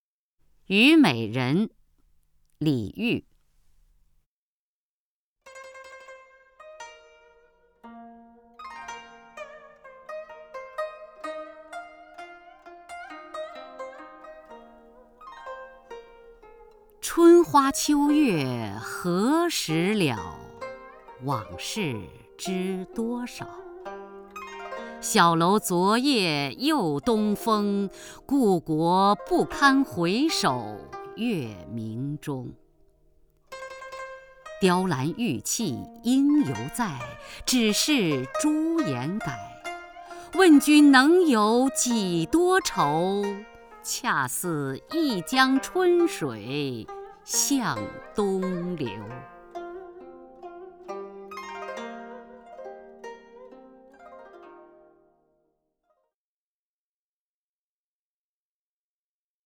首页 视听 名家朗诵欣赏 雅坤
雅坤朗诵：《虞美人·春花秋月何时了》(（南唐）李煜)